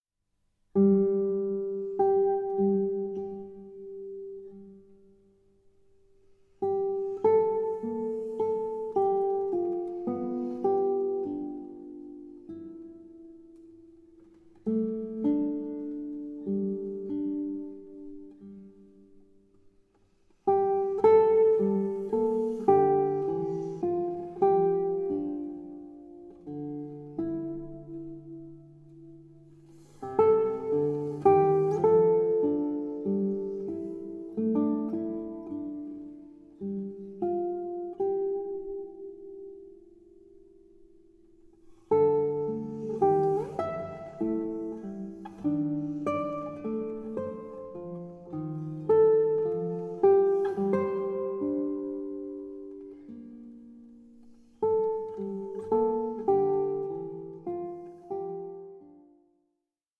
for guitar